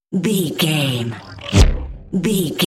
Sci fi pass by insect wings fast
Sound Effects
Atonal
Fast
futuristic
pass by